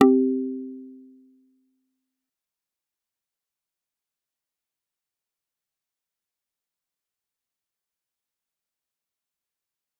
G_Kalimba-C4-f.wav